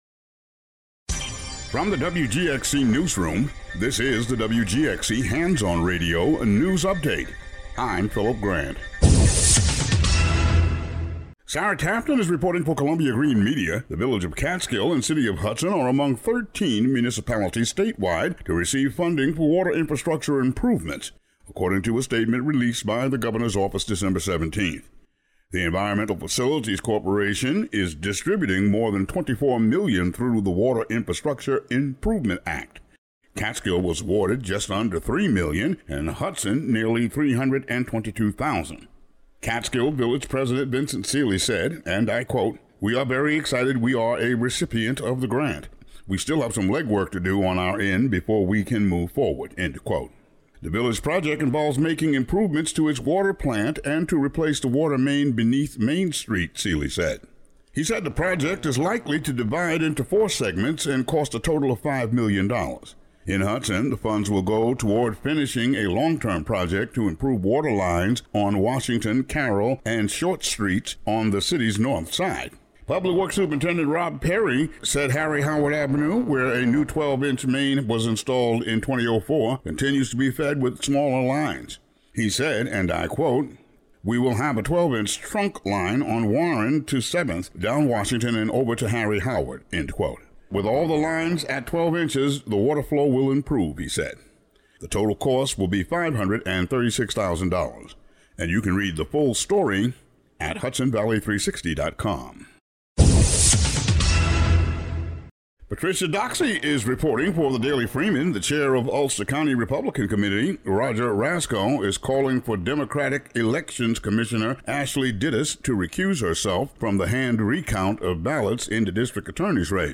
The audio version of the news update for Thu., Dec. 19.